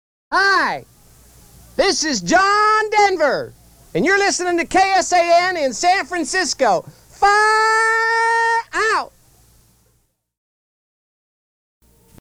John Denver: Imitation of the country legend.